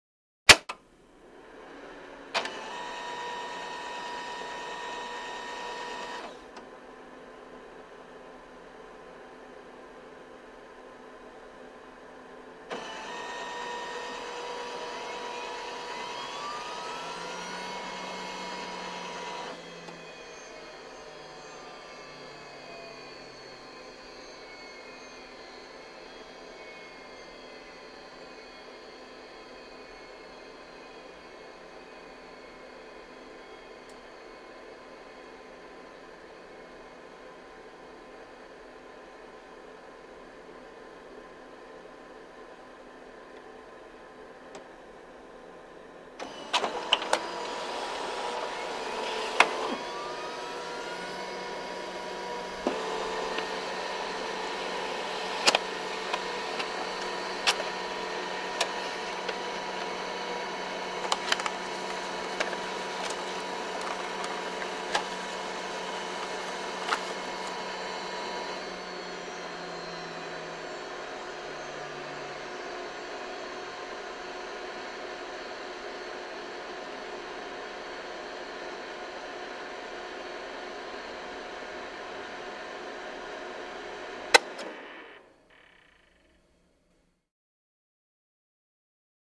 Laser Printer; Desktop Laser Printer; Turn On / Reset / Print One Page Of Text / Turn Off, Close Perspective.